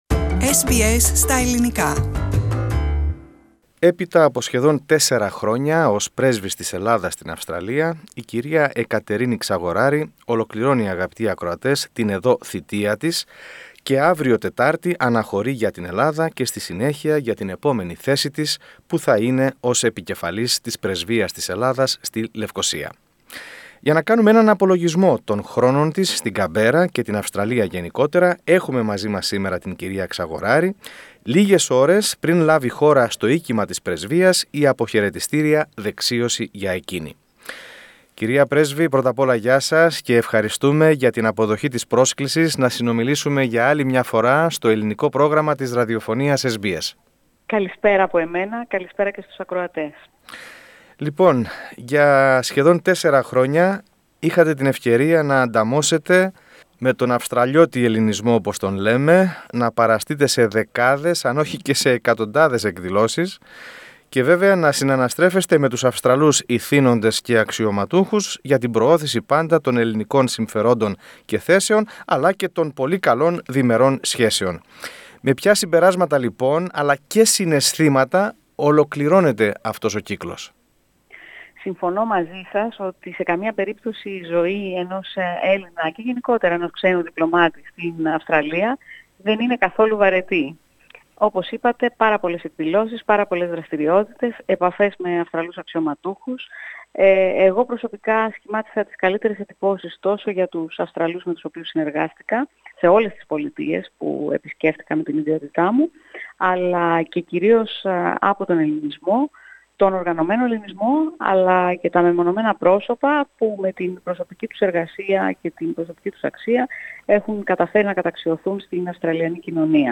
Η κυρία Ξαγοράρη λίγες ώρες πριν λάβει χώρα στο οίκημα της πρεσβείας η αποχαιρετιστήρια δεξίωση για εκείνη, μίλησε στο Ελληνικό Πρόγραμμα της Δημόσιας Αυστραλιανής Ραδιοφωνίας SBS, κάνοντας τον δικό της απολογισμό.